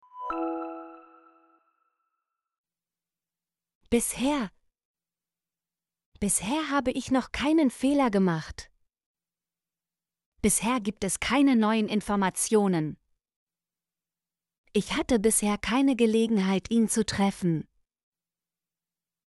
bisher - Example Sentences & Pronunciation, German Frequency List